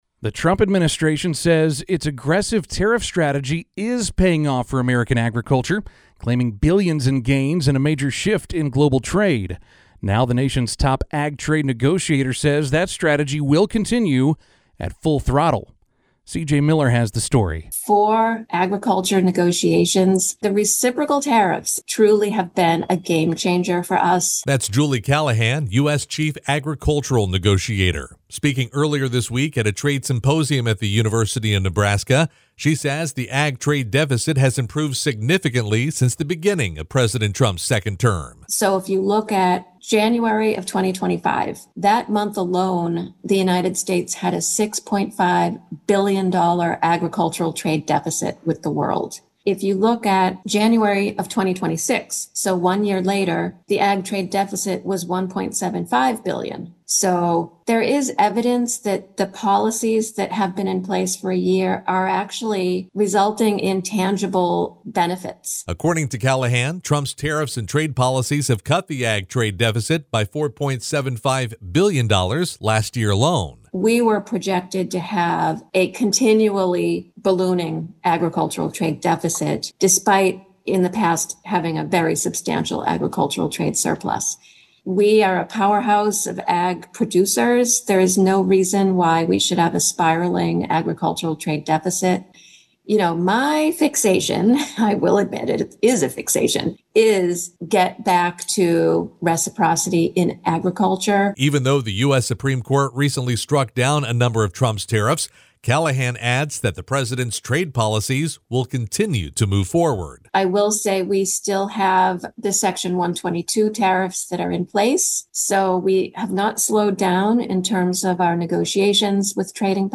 “For agriculture negotiations, the reciprocal tariffs truly have been a game changer for us,” said Julie Callahan, Chief Agricultural Negotiator with the Office of the U.S. Trade Representative (USTR), speaking earlier this week at a trade symposium at the University of Nebraska.